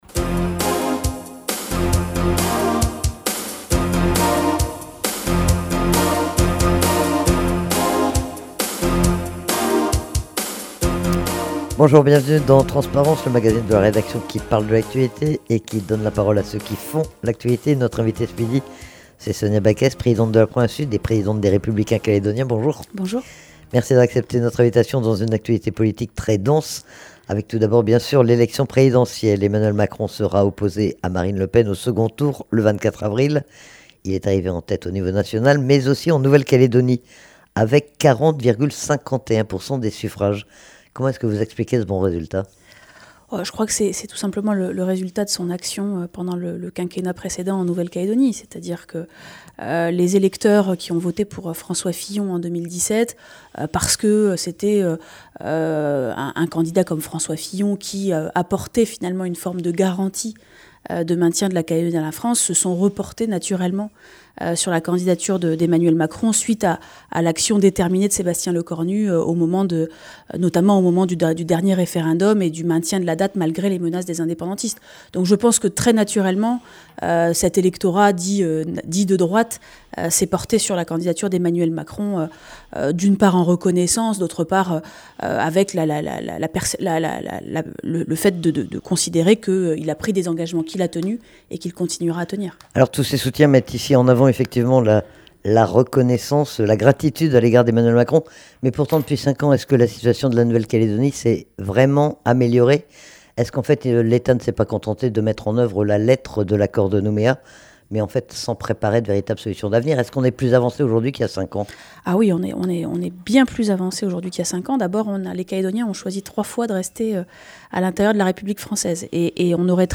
Sonia Backès a été interrogée sur l'actualité politique, entre les deux tours de la présidentielle et alors qu'un nouveau groupe Loyaliste a été créé au congrès. Mais aussi sur l'actualité de la province Sud.